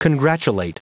Prononciation du mot congratulate en anglais (fichier audio)
Prononciation du mot : congratulate